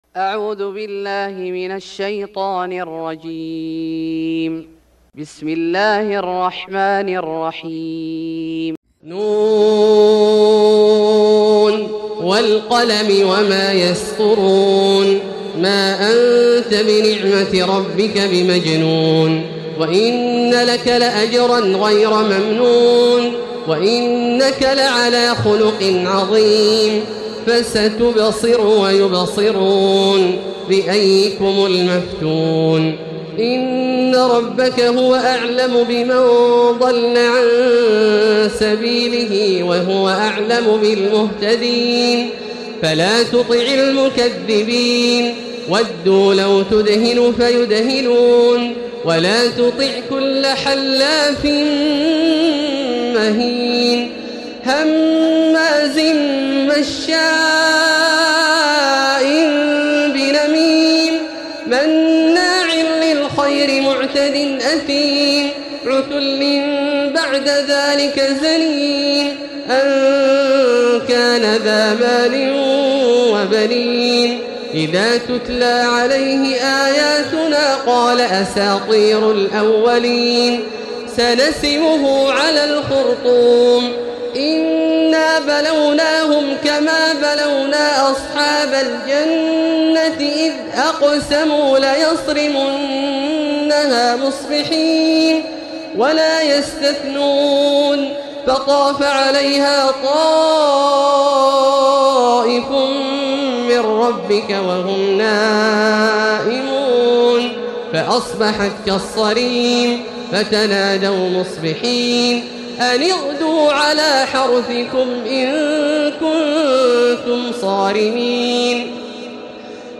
سورة القلم Surat Al-Qalam > مصحف الشيخ عبدالله الجهني من الحرم المكي > المصحف - تلاوات الحرمين